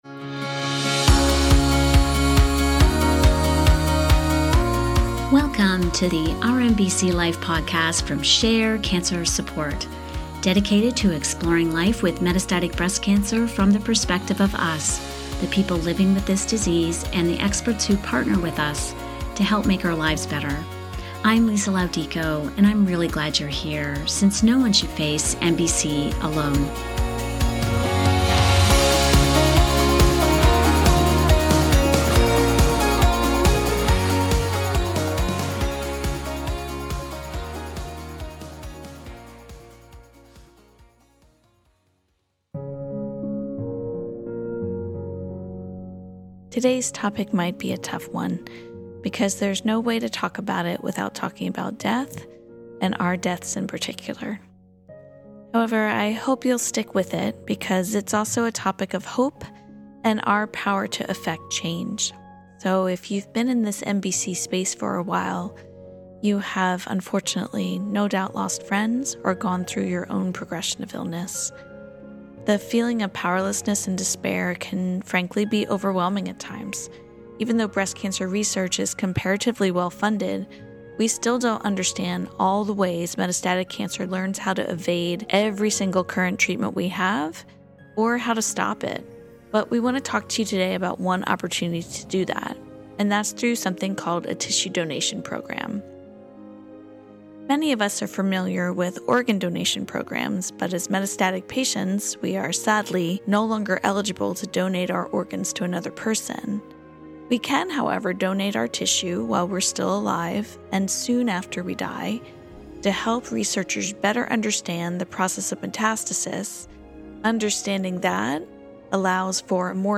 To learn more about tissue donation programs and why they are so essential to scientific discoveries, we talk with patient advocates